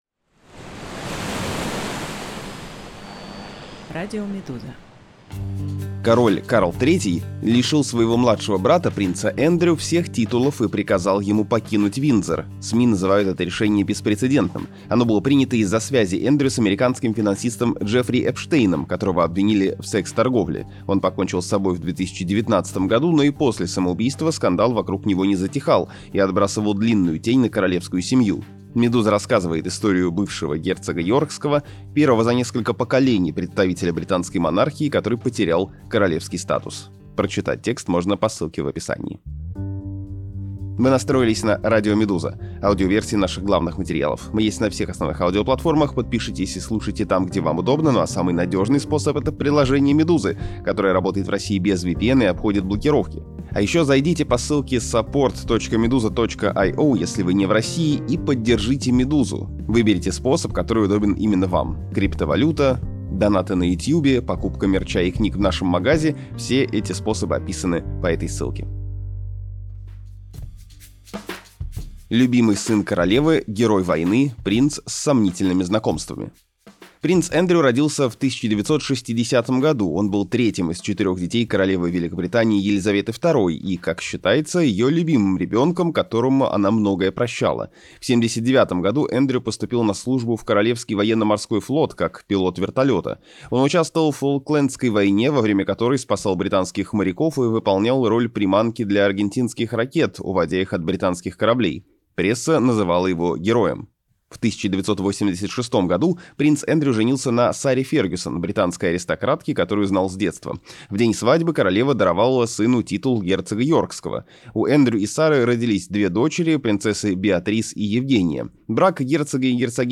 Аудиоверсию этого текста слушайте на «Радио Медуза» подкасты Почему принца Эндрю лишили всех титулов?